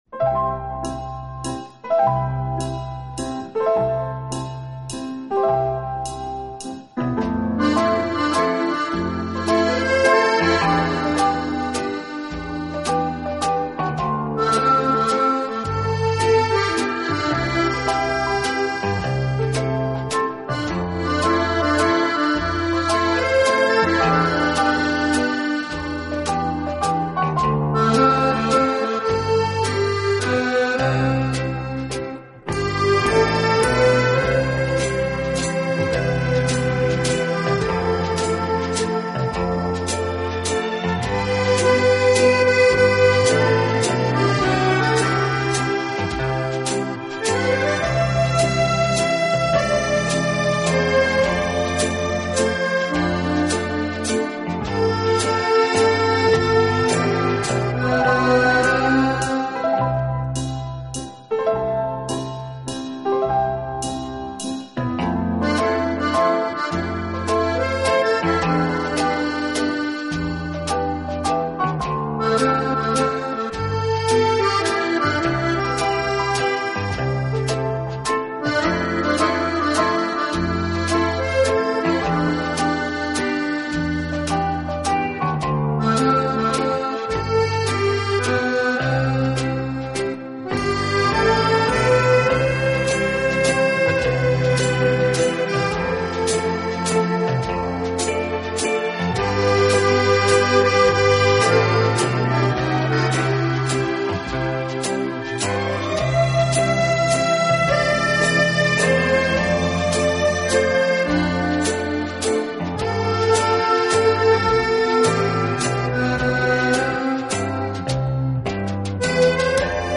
Genre: Instrumental, Classical